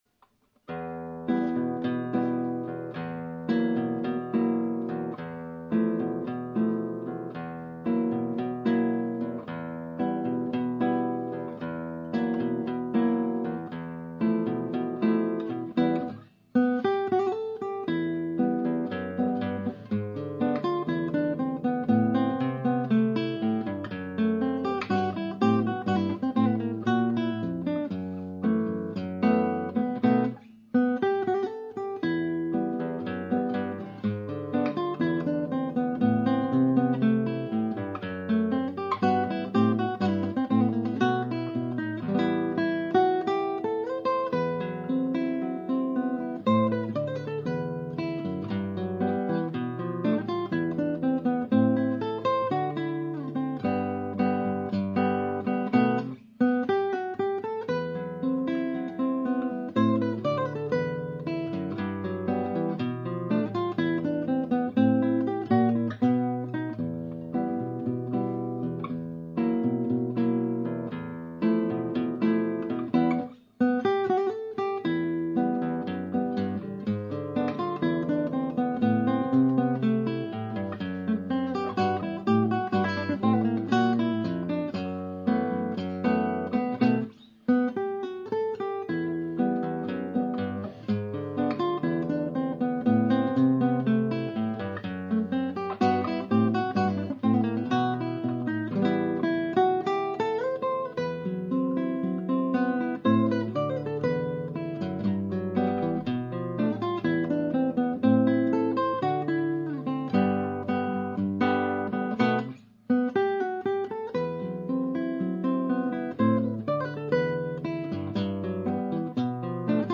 Latin and jazz for guitar solo